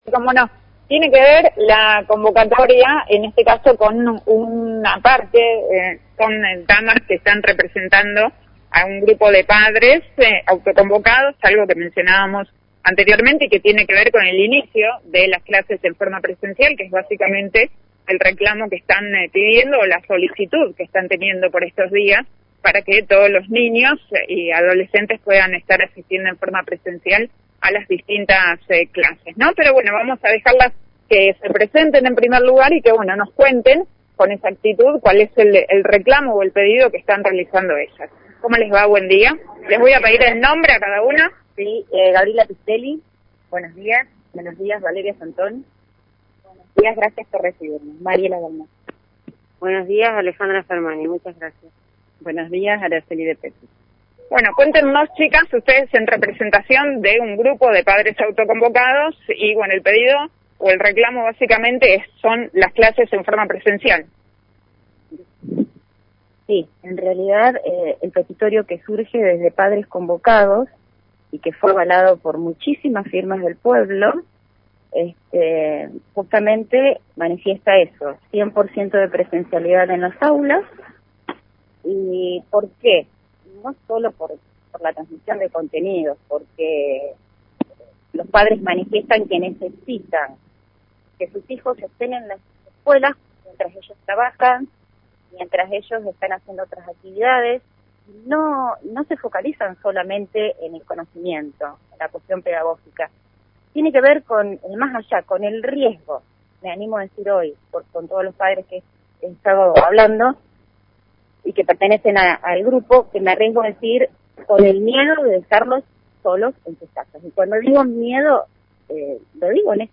En el día de ayer, Miércoles, recibimos la visita de un grupo de madres de niños y adolescentes en edad escolar que exigen a las autoridades la presencialidad de los alumnos en las escuelas.